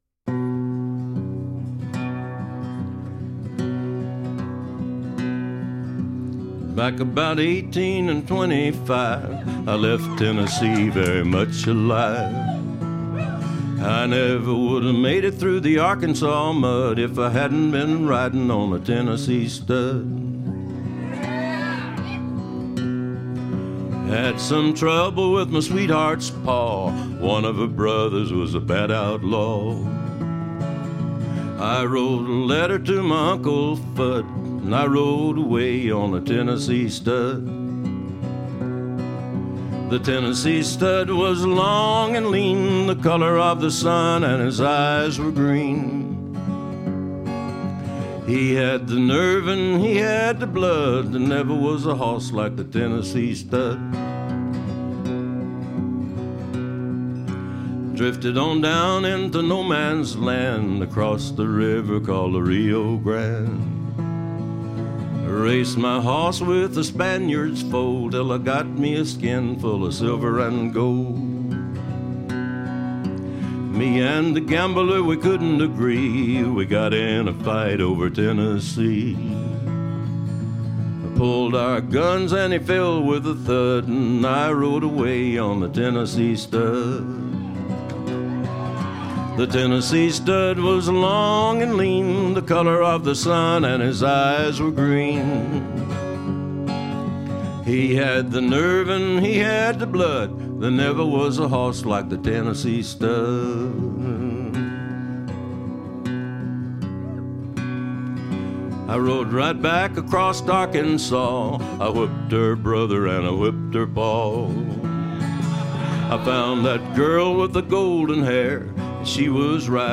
country کانتری